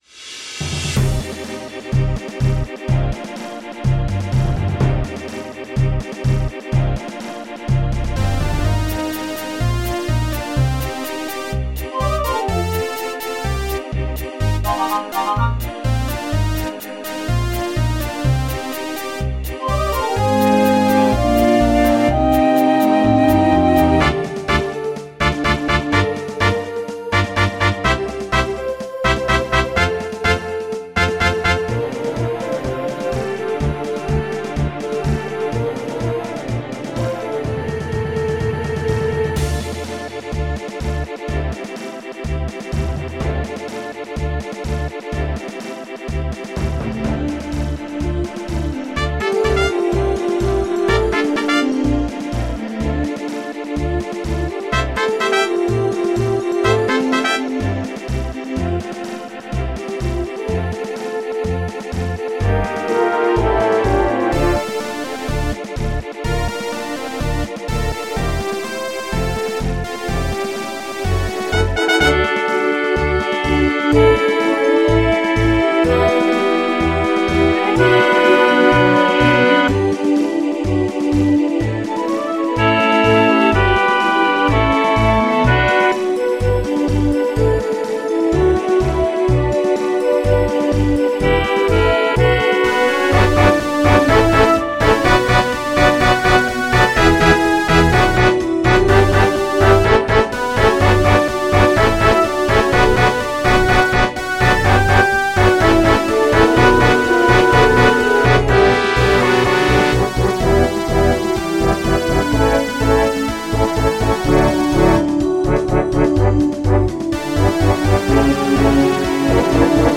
MIDI 114.23 KB MP3